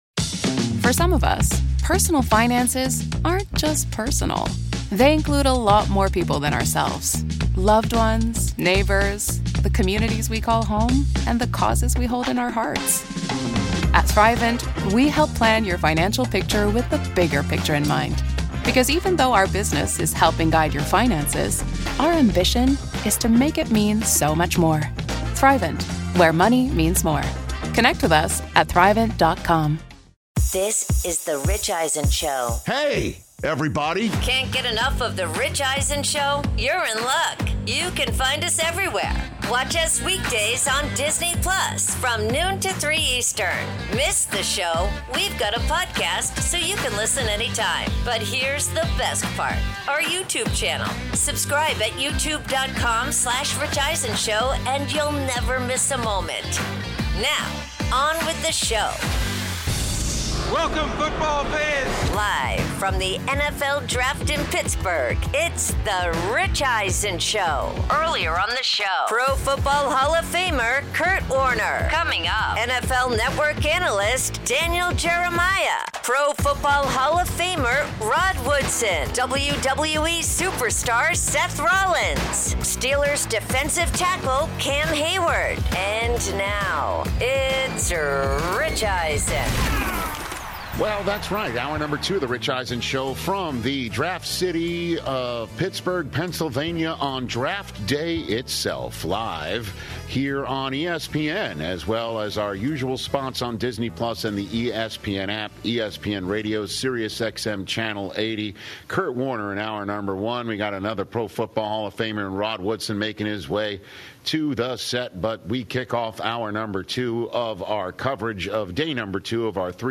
Live from the NFL Draft in Pittsburgh where Rich and NFL Network Analyst Daniel Jeremiah preview the first round including how trades could reshape how the top 10 picks shake out, if Caleb Downs could fall to the Cowboys at #12, why the Arizona Cardinals trading back into the 1st round for Ty Simpson makes sense, and more.